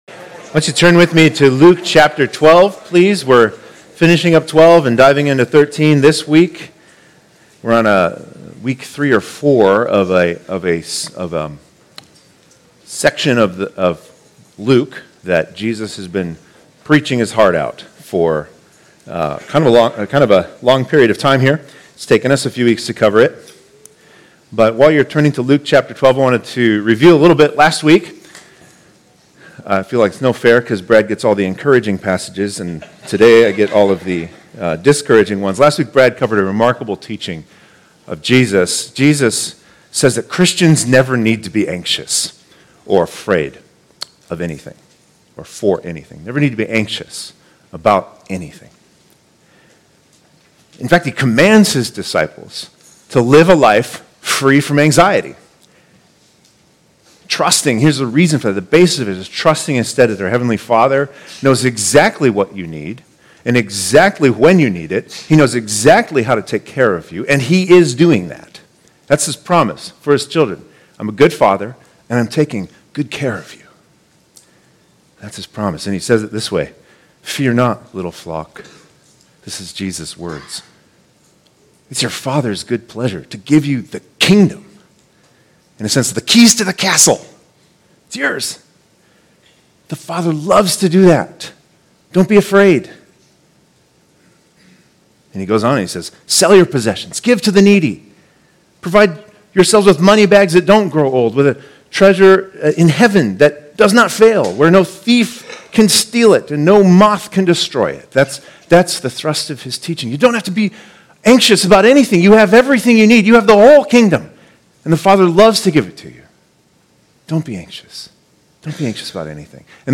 2022 Stay up to date with “ Stonebrook Church Sermons Podcast ”